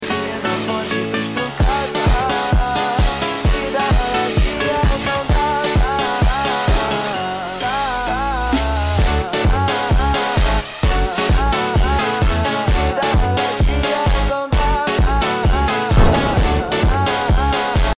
Fpv Kamikaze Drone Footage ! sound effects free download